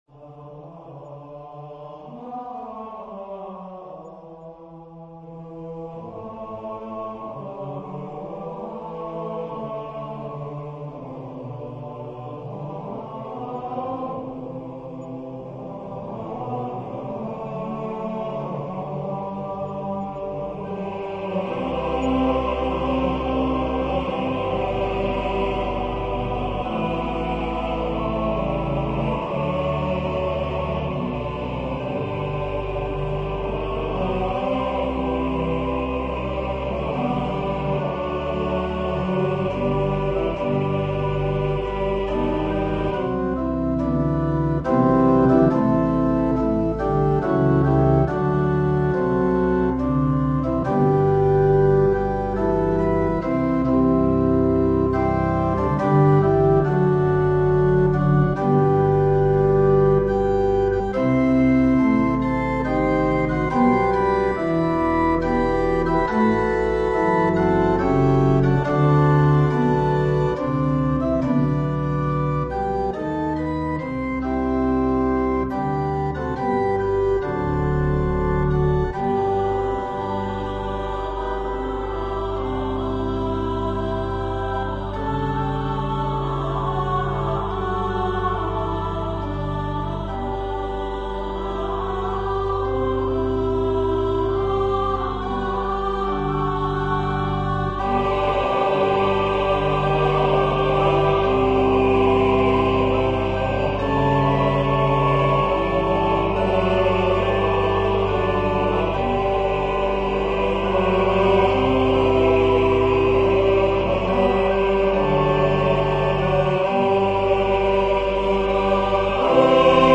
This is an arrangement for SATB choir and organ.
Voicing/Instrumentation: SATB